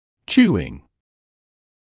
[‘ʧuːɪŋ][‘чуːин]жевание; инговая форма от to chew